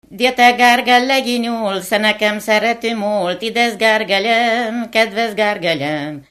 Moldva és Bukovina - Moldva - Szabófalva
Műfaj: Gergely-tánc
Stílus: 7. Régies kisambitusú dallamok
Kadencia: #4 (#4) 5 1